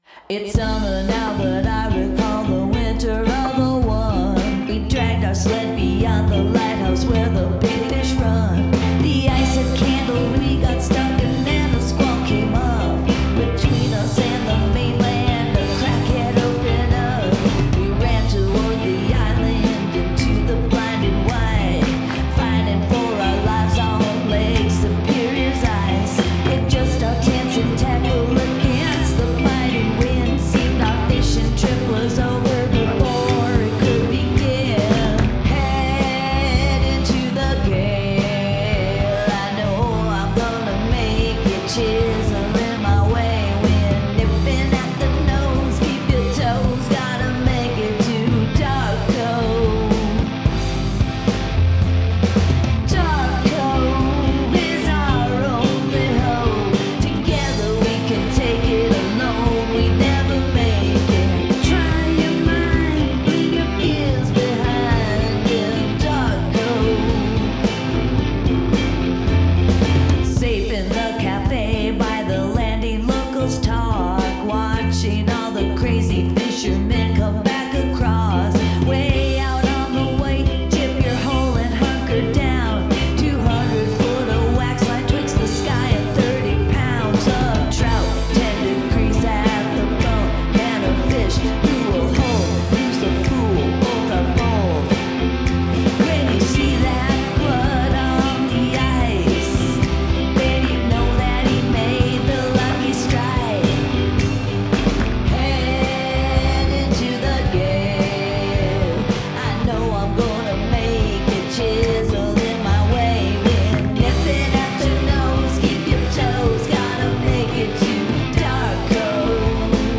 Make use of handclaps and snaps